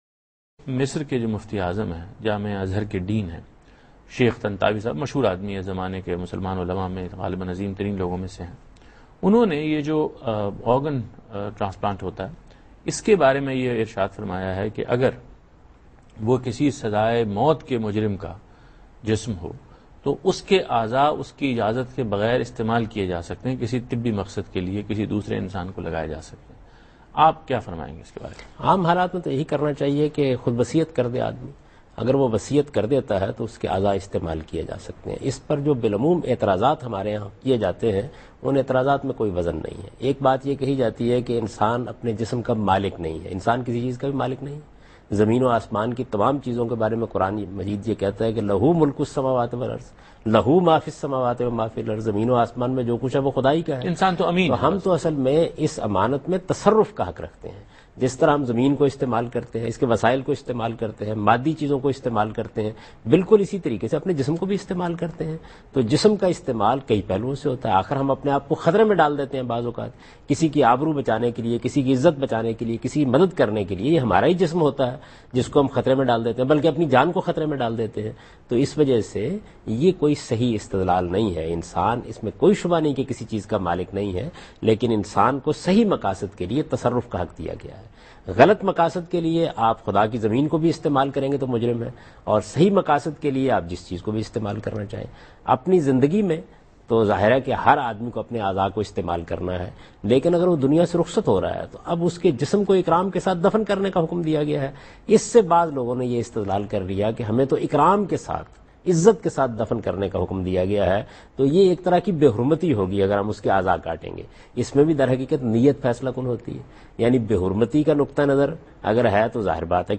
Javed Ahmad Ghamidi answers a question about Use of the Organs of a Criminal" in program Deen o Daanish on Dunya News.